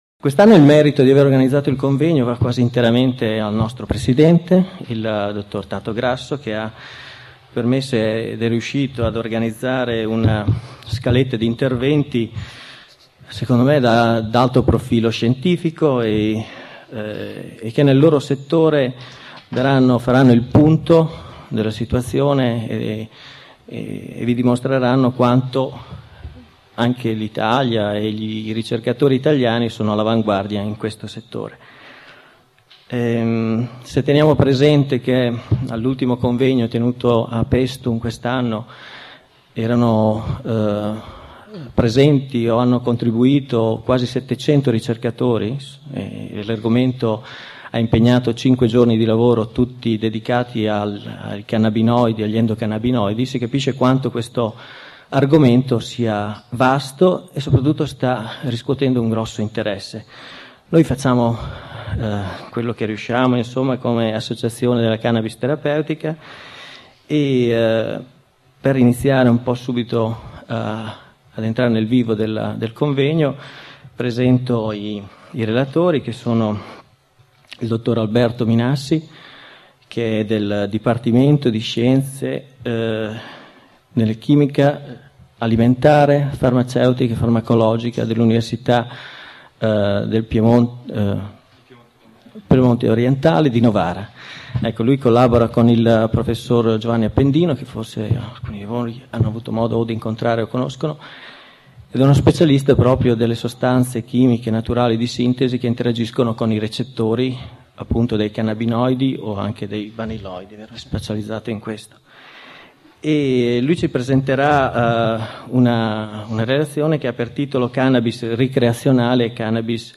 16° Salone Internazionale del Naturale - Alimentazione, Salute, Ambiente (SANA)
Bologna 12 settembre 2004